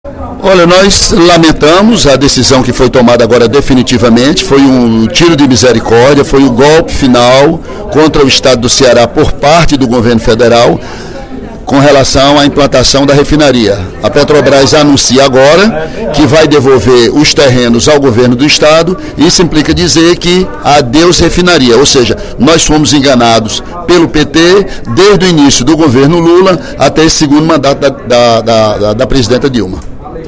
Durante o primeiro expediente da sessão plenária desta quinta-feira (09/04), o deputado Ely Aguiar (PSDC) criticou a devolução do terreno onde seria construída a refinaria Premium, da Petrobras, para o Governo do Estado.